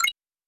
Super Smash Bros. game sound effects
Pause.wav